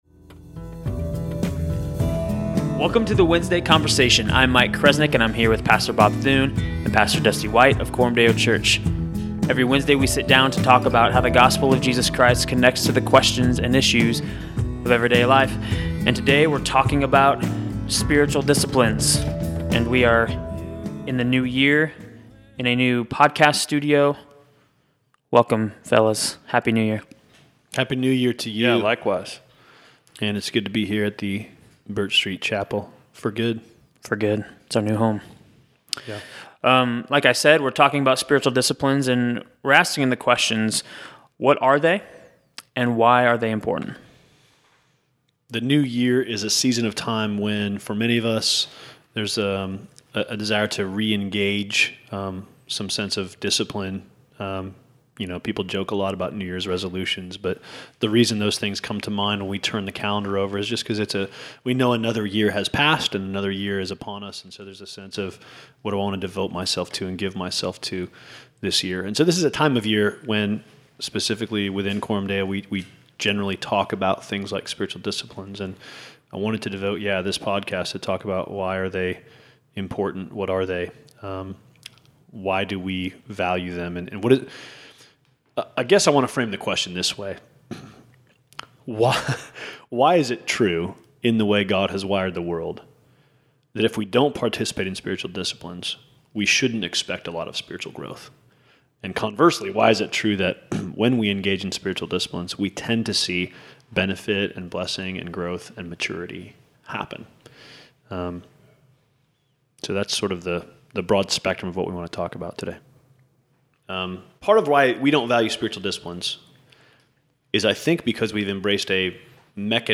We start the New Year off right with a new podcast studio (echoes and construction sounds are free...) and a new topic of conversation, Spiritual Disciplines.